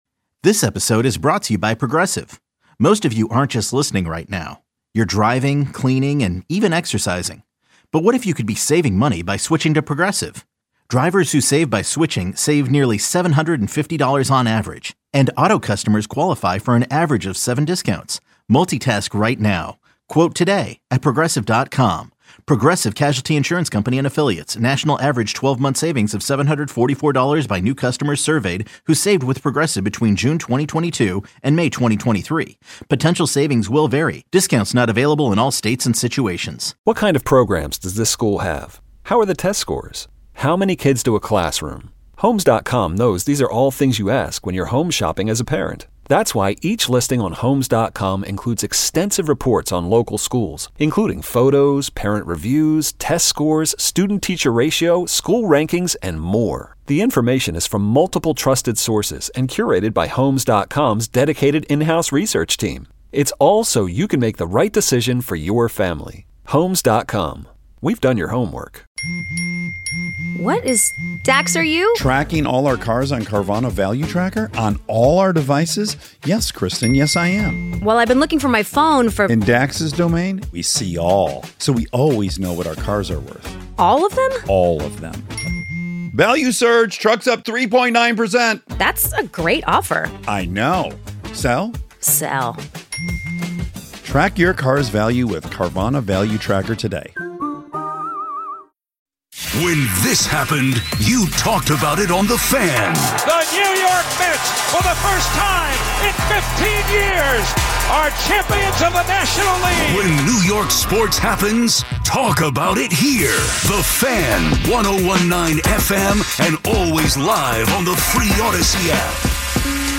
(INTERVIEW):